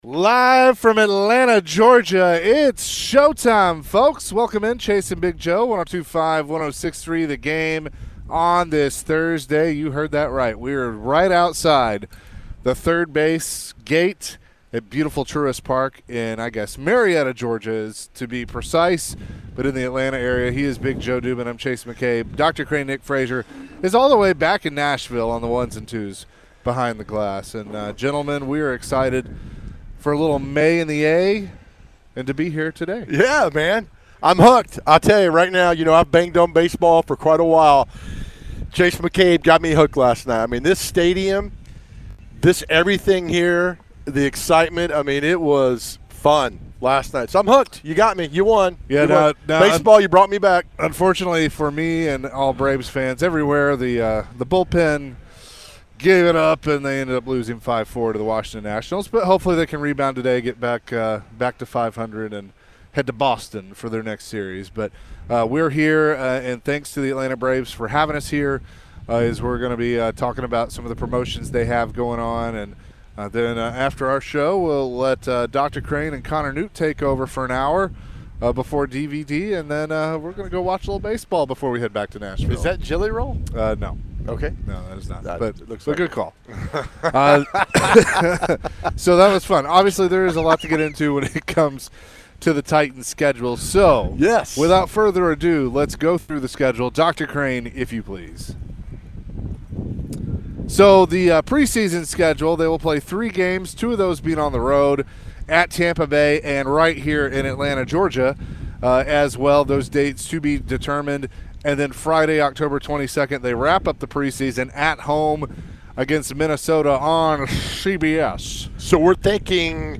Later in the hour, the guys discussed their question of the day and answered some phones.